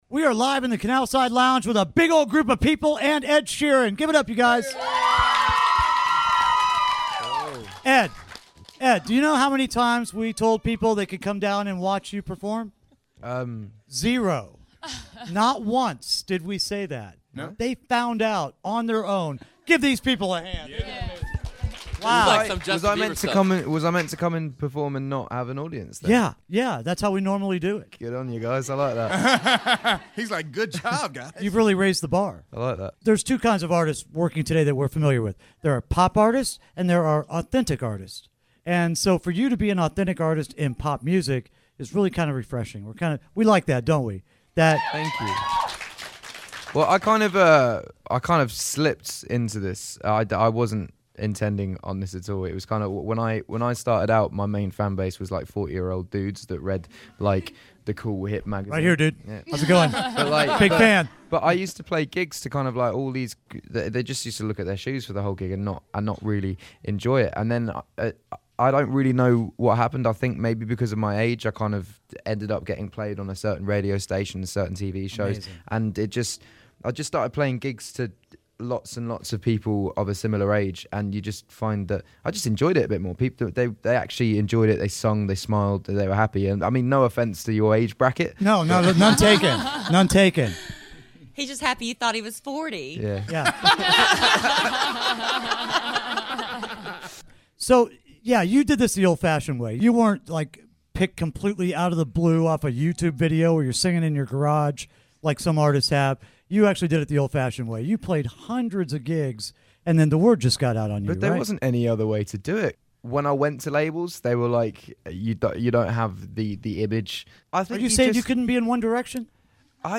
Ed Sheeran Interview
Kidd Kraddick in the Morning interviews Ed Sheeran!